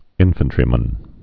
(ĭnfən-trē-mən)